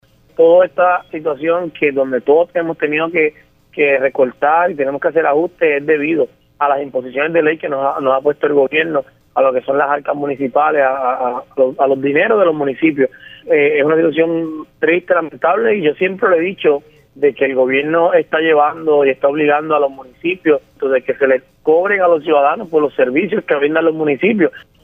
Sin embargo, el alcalde criticó las recientes imposiciones del Gobierno a las arcas municipales y que, a su juicio, no tardarán mucho en provocar el encarecimiento de servicios básicos a la ciudadanía.